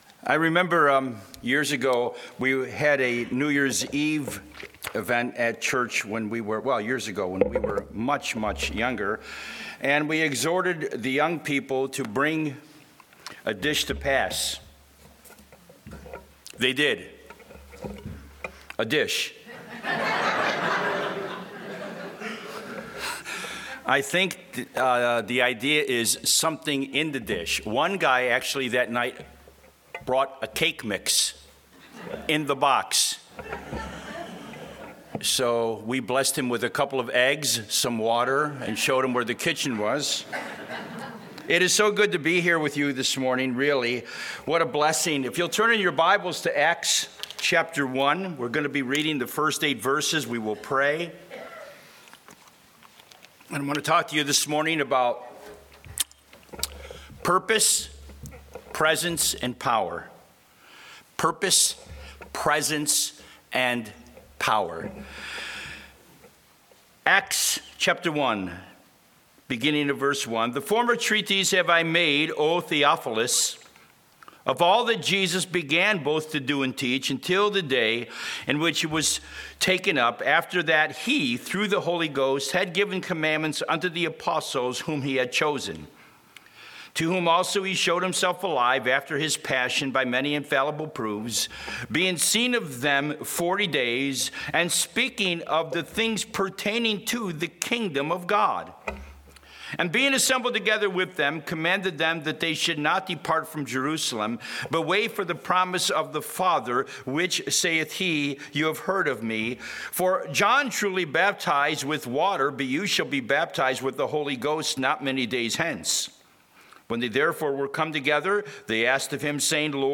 Message
A message from the series "Guest Speaker."